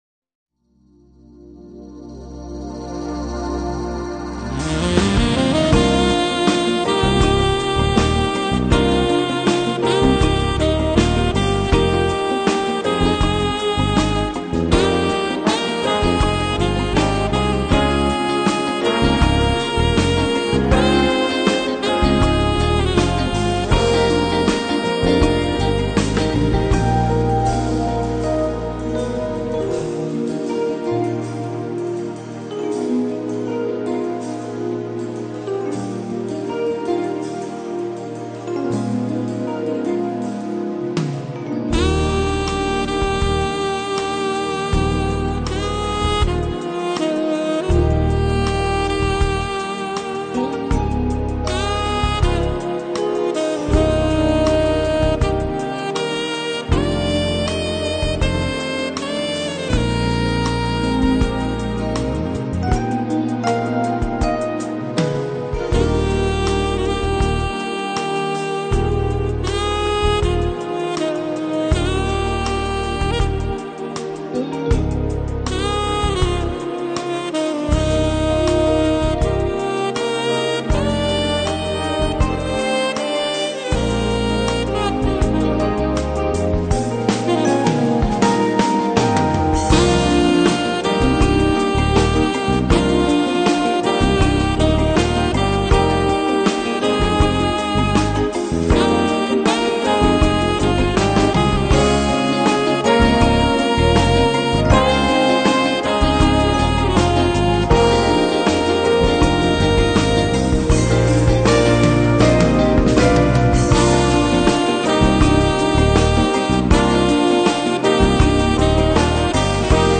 旋律轻柔、华润
更 具浪漫温馨之色调。